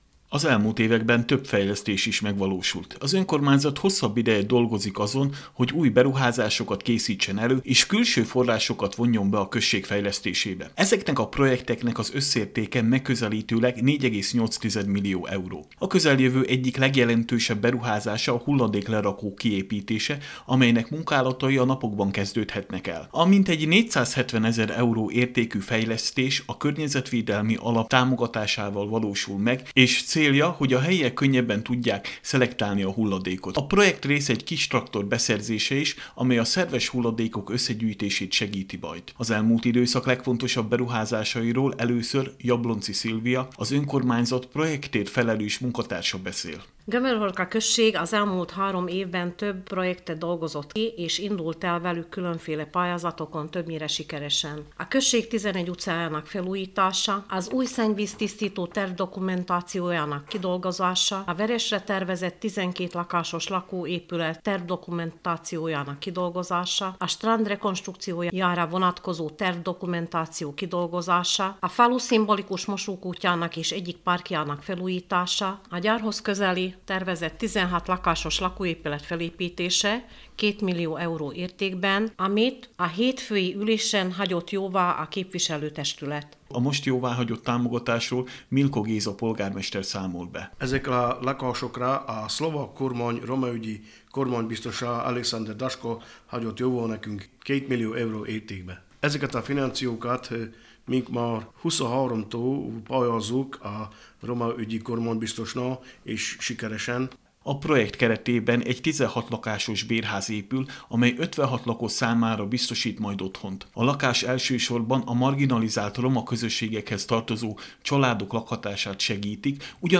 tudósítása